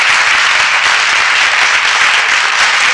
Applauding Sound Effect
Download a high-quality applauding sound effect.
applauding.mp3